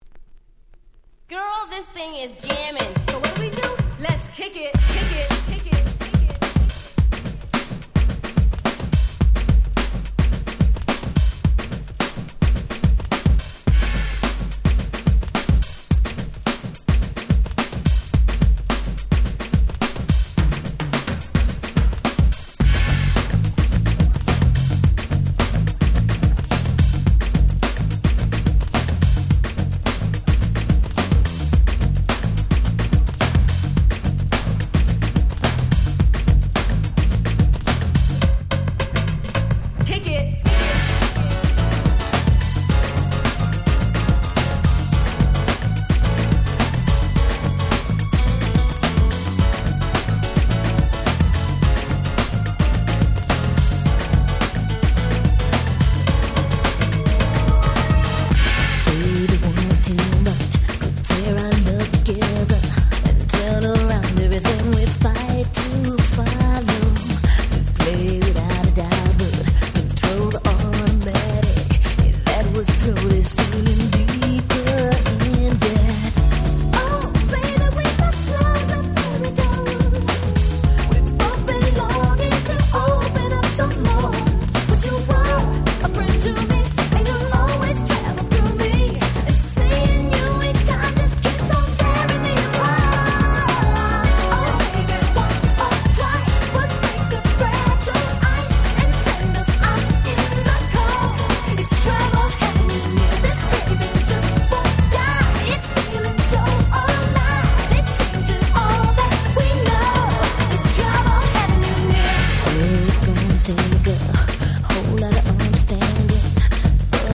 91' Nice R&B / New Jack Swing !!
切ないMelodyに疾走感のあるBeat、最高！！
90's ハネ系 NJS ニュージャックスウィング